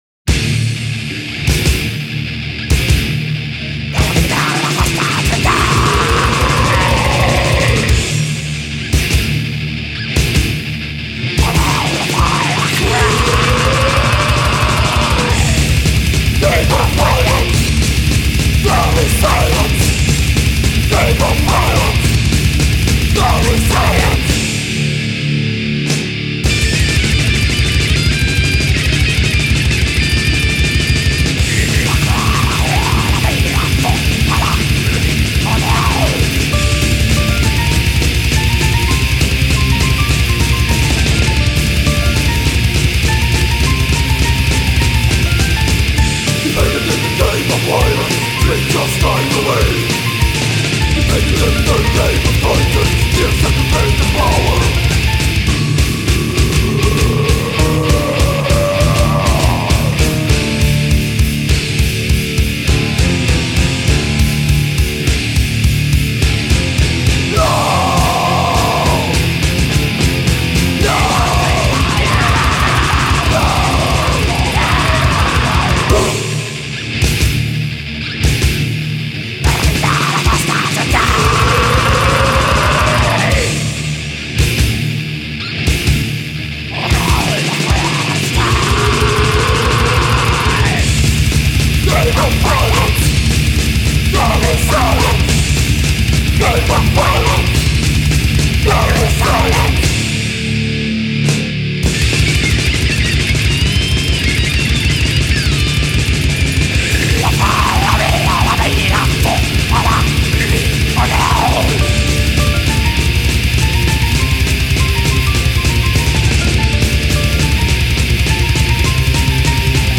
Death Meral